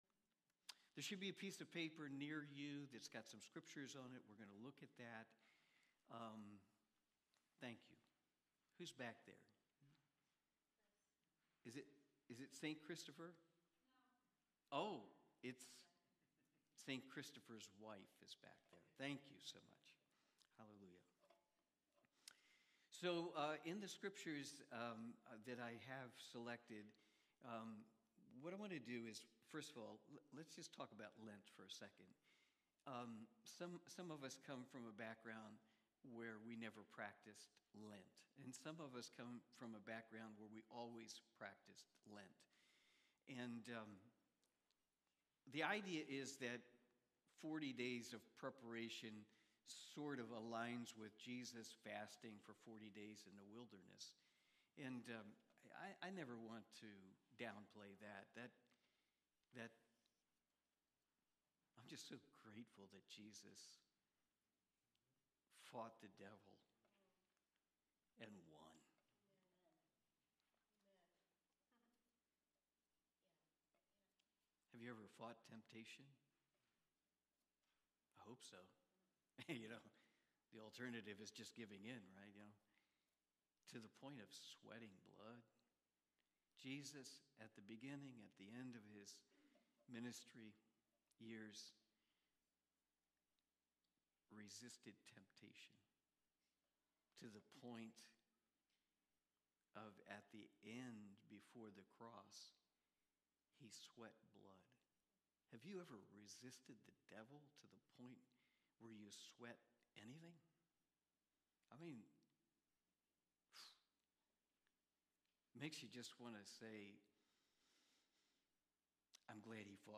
1 Corinthians Watch Listen Save Cornerstone Fellowship Sunday morning service, livestreamed from Wormleysburg, PA.